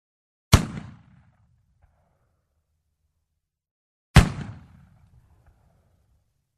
Звуки ружья
Звук выстрела охотничьей двухстволки